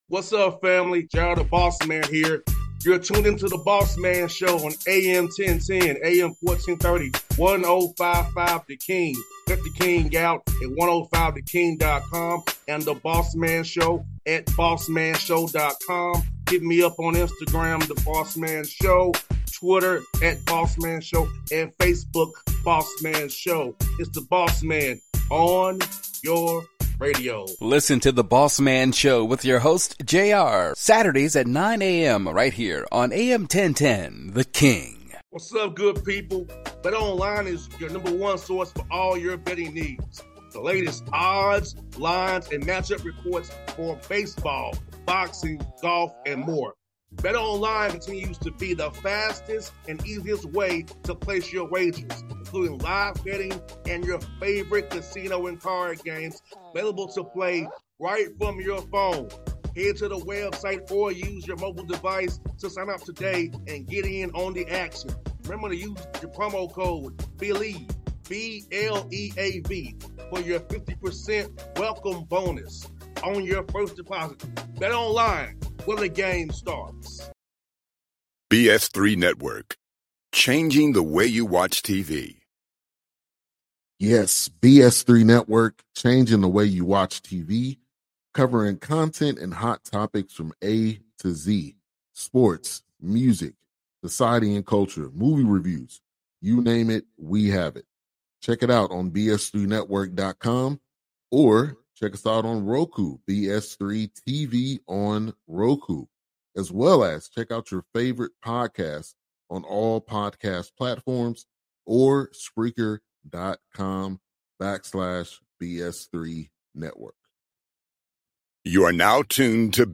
Lamont Paris Interview